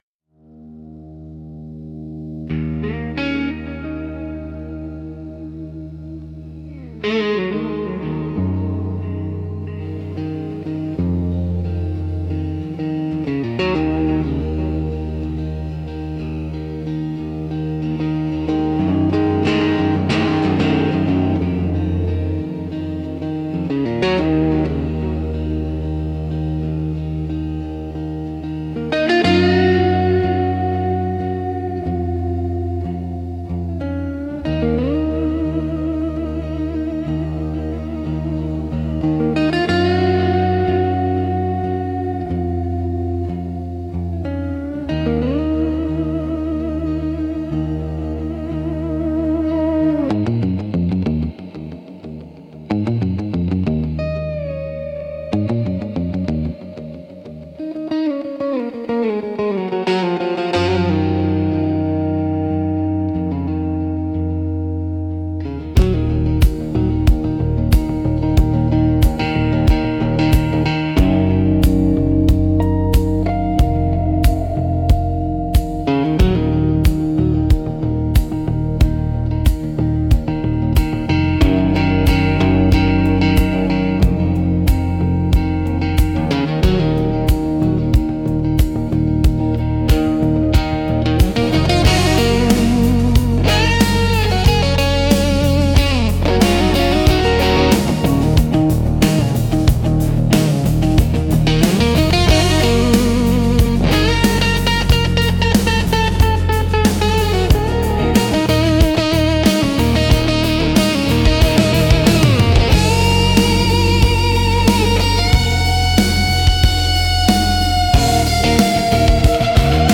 Instrumental - The Last Way Station 5.52